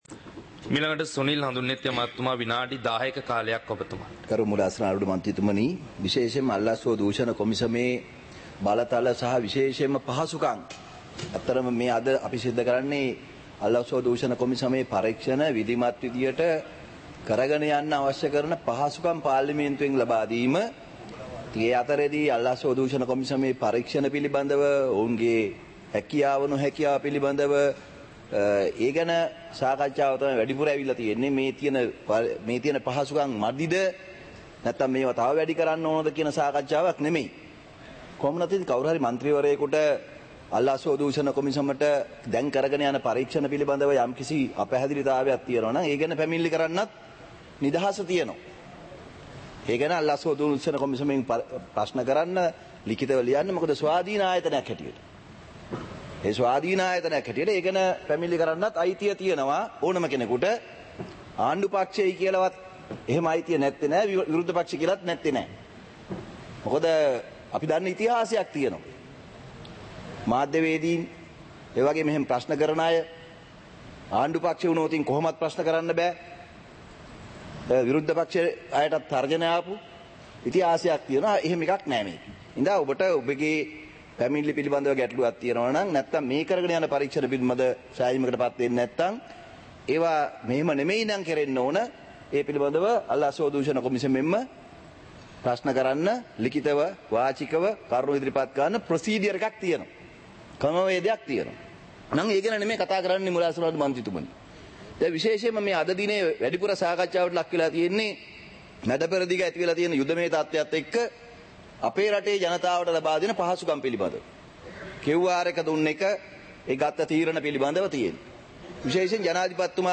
Parliament Live - Recorded